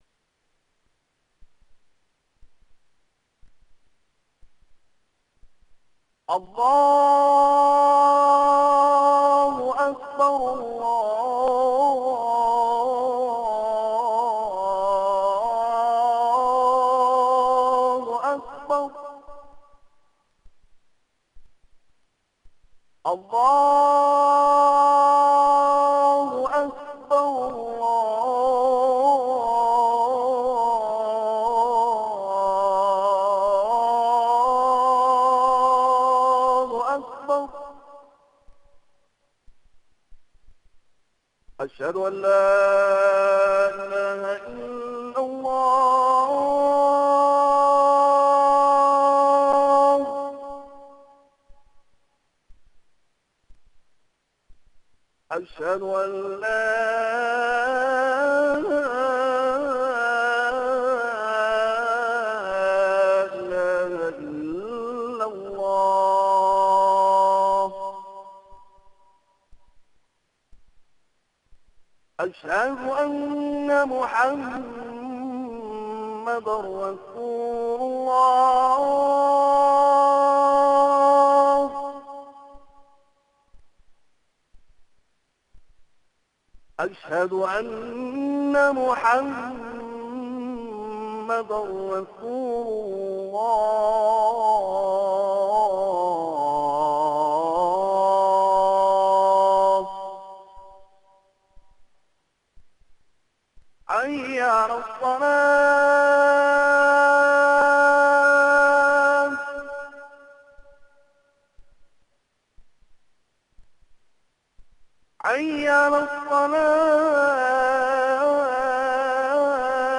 Q.        What is Adhaan (call for prayer) ?
A.        When the time for salaah comes, a man stands up before the prayer and calls aloud these words [1](
aqsaadhaan.rm